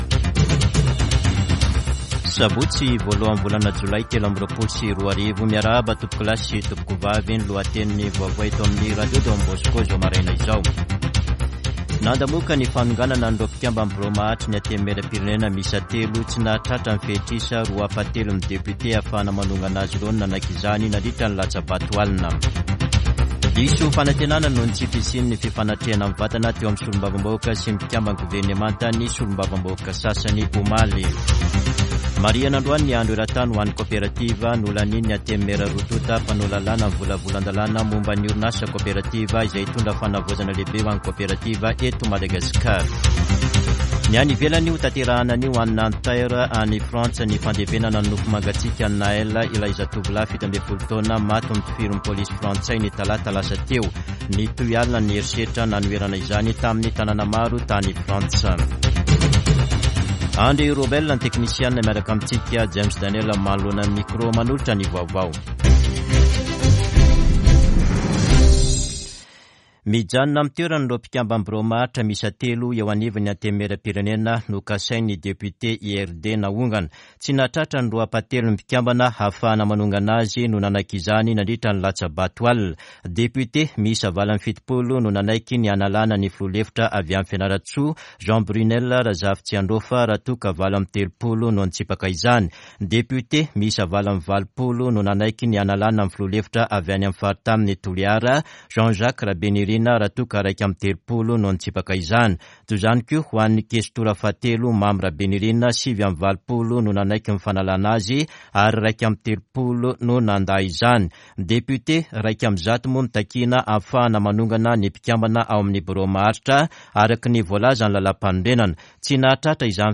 [Vaovao maraina] Sabotsy 1 jolay 2023